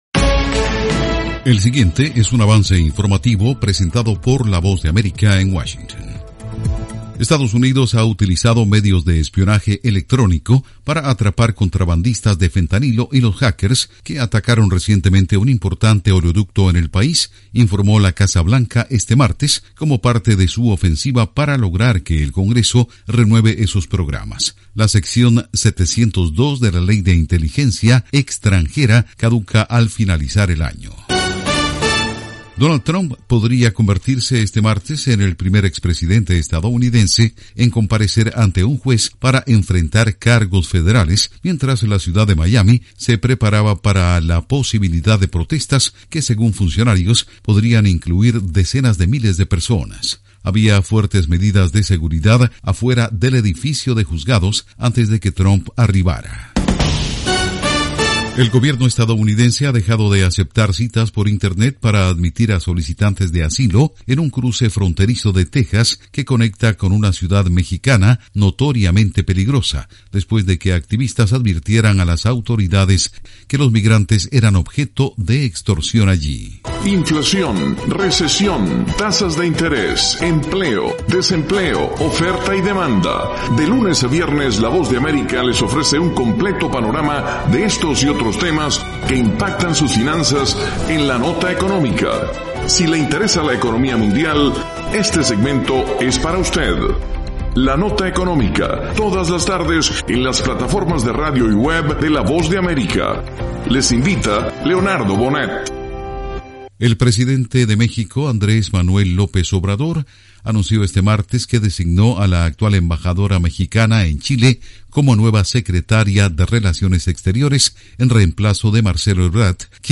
Avance Informativo 2:00 PM
El siguiente es un avance informativo presentado por la Voz de América en Washington.